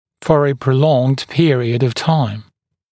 [fɔːrə prə’lɔŋd ‘pɪərɪəd əv taɪm][фо:рэ прэ’лонд ‘пиэриэд ов тайм]в течение длительного времени